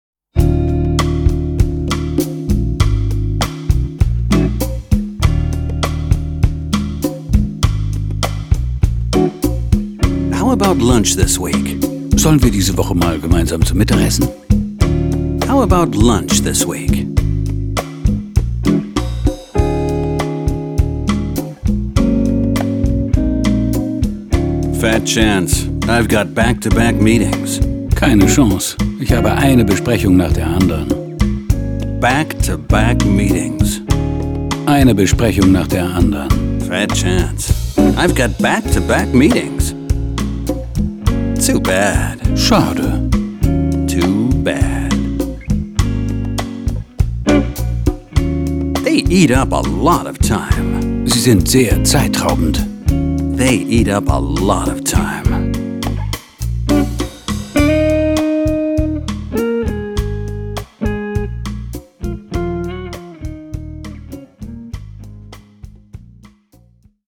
Talk of the Town.Coole Pop & Jazz Grooves / Audio-CD mit Booklet
Mit den supercoolen Pop & Jazz Grooves können Sie ganz entspannt und in bester Laune Englisch lernen.
Die einzigartige Mischung aus Hörbuch, Musik und Sprachtrainer verspricht puren Lerngenuss.